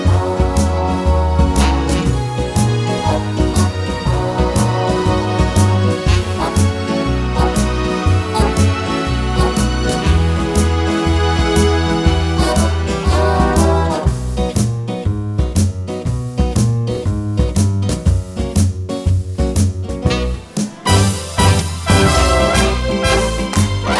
no Backing Vocals Jazz / Swing 2:30 Buy £1.50